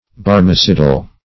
Search Result for " barmecidal" : The Collaborative International Dictionary of English v.0.48: Barmecidal \Bar"me*ci`dal\, a. [See Barmecide .]